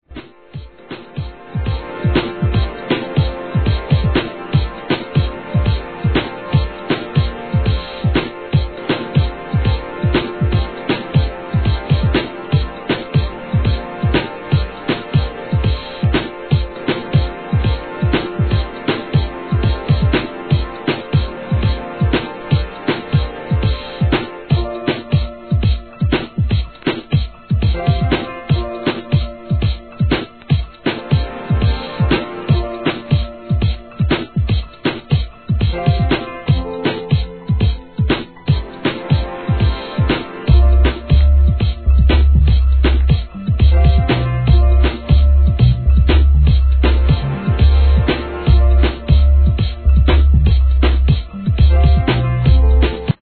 荘厳な雰囲気のピアノ＆ホーンが印象的な、スペーシーなBROKENBEATS！ブレイク・ビーツ